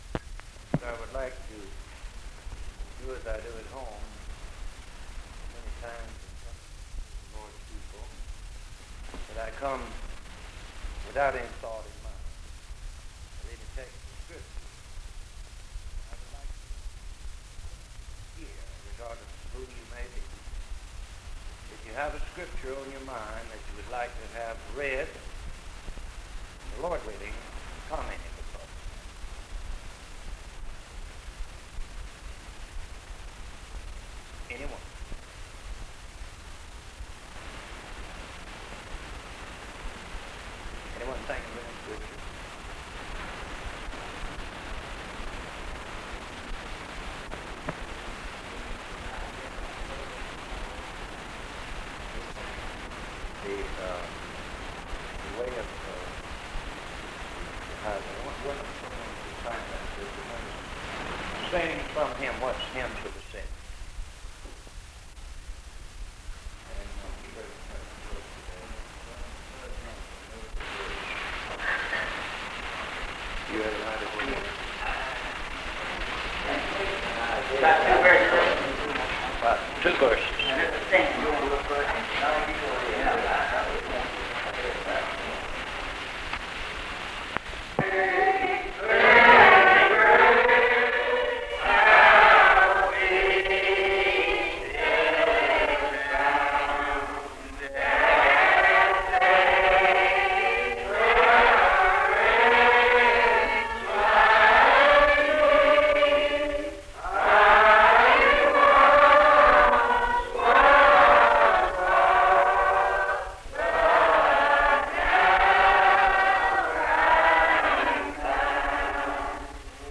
From a collection of sermons